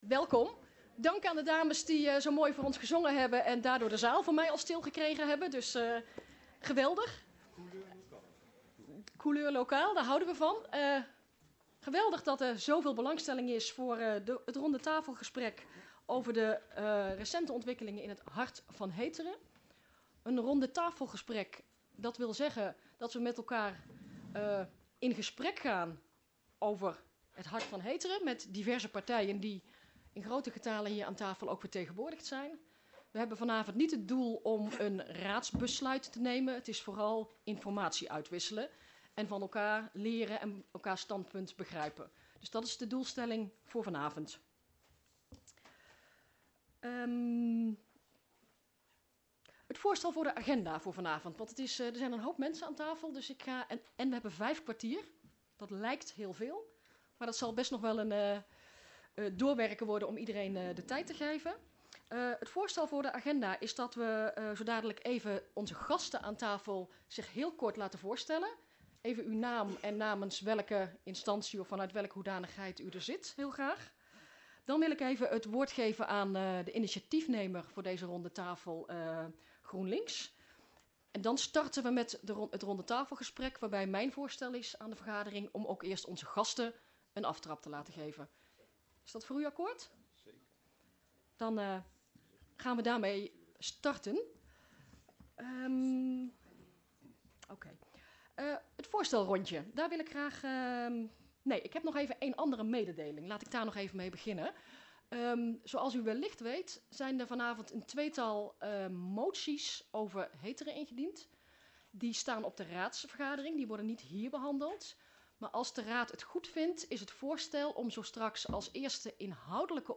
Locatie gemeentehuis Elst Voorzitter mevr. J. Rouwenhorst Toelichting RTG Recente ontwikkelingen Hart van Heteren Agenda documenten 18-02-06 Opname 1.